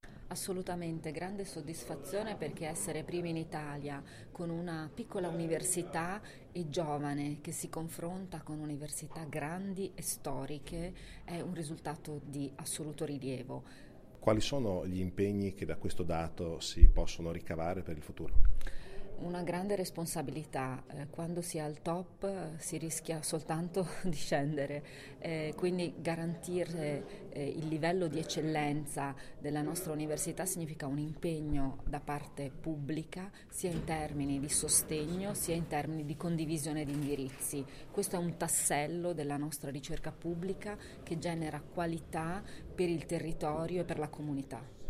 In allegato l'intervista audio all'assessora